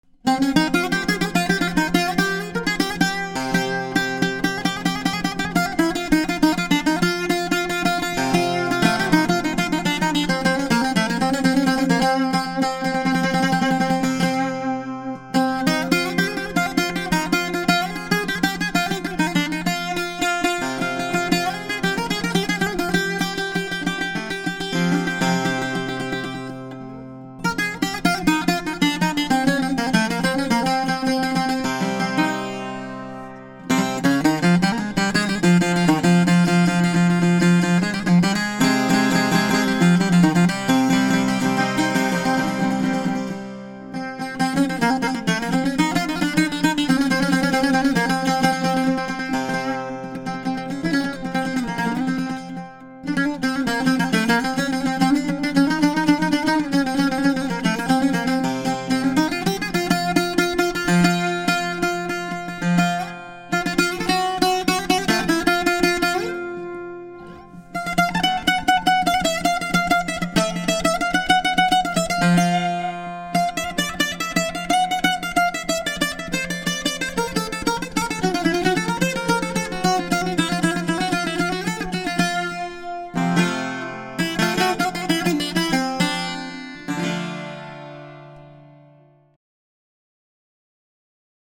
modern - conventional 8 strings bouzouki made by maple and walnut with a new wave desing and with the following specifications:
• Speaker wood: maple - walnut